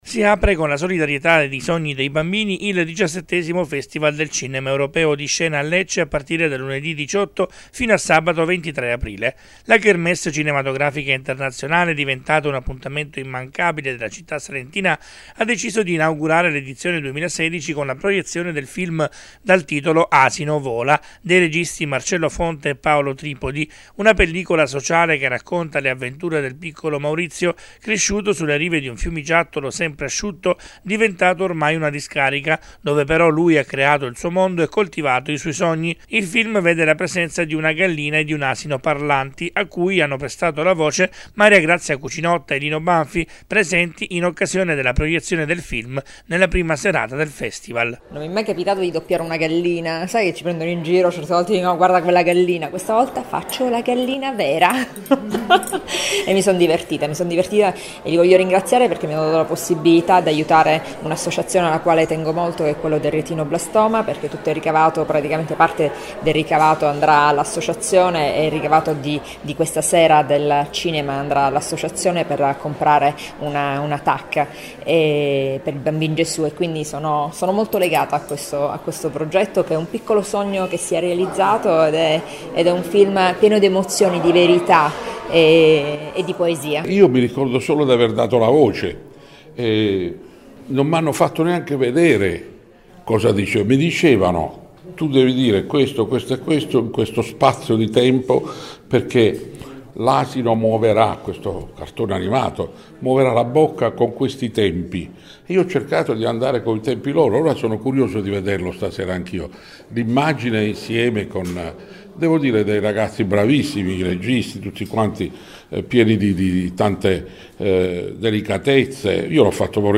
In tarda mattinata i due attori incontrano la stampa.
Festival-Cinema-Europeo-Lino-Banfi_-Maria-Grazia-Cucinotta.mp3